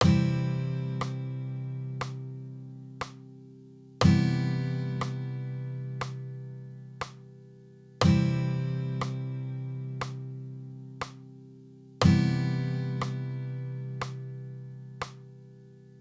The audio samples that follow each pair will sound out the traditional chord first followed by the easy version.
C and C easy chords
cmaj-easy.wav